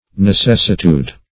Necessitude \Ne*ces"si*tude\, n. [L. necessitudo, fr. necesse.